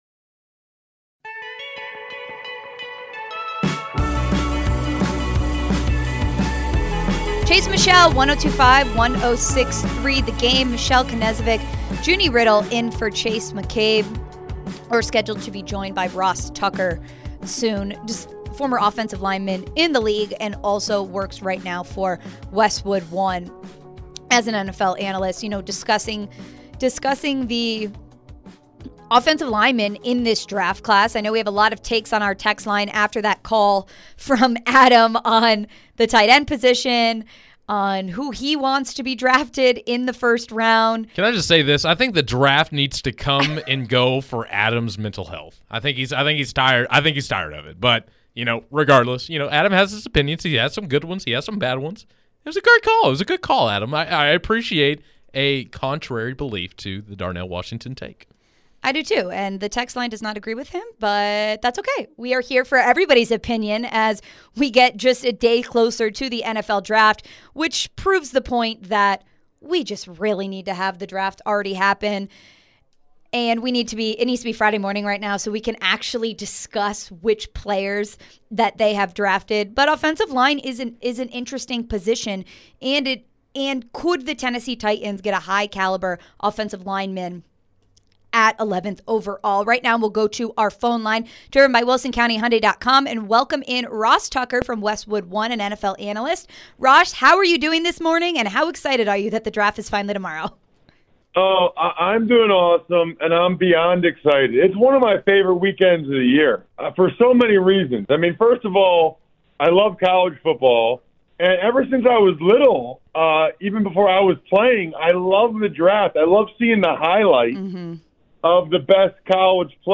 Ross Tucker Interview (4-26-23)